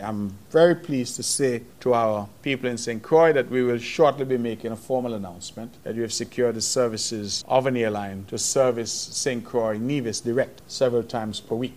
The voice of Premier of Nevis, Hon. Mark Brantley.